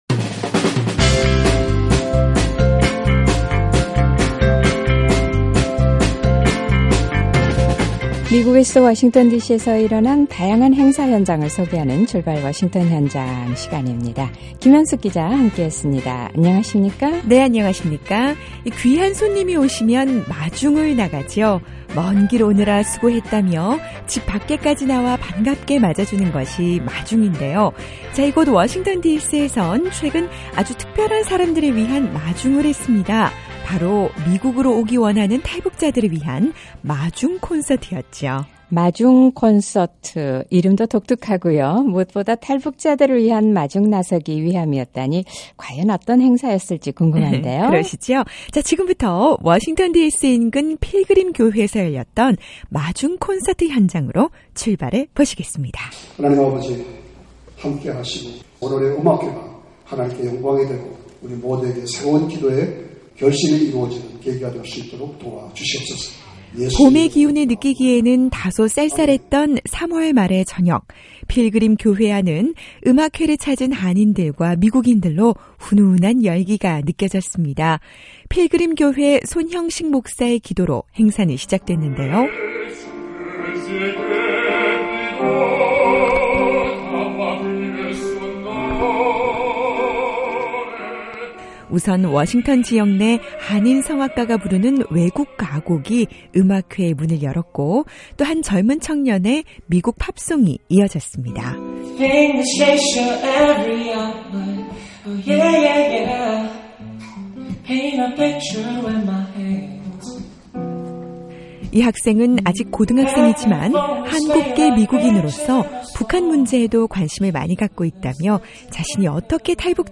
어려움에 처한 한 탈북자 가족을 돕기 위한 자선음악회 ‘마중 콘서트’가 워싱턴 디씨 인근에서 열렸습니다.
과연 어떤 음악과 이야기가 있었는지 마중 콘서트 현장으로 출발해보시죠!